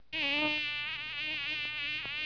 mosquit2.wav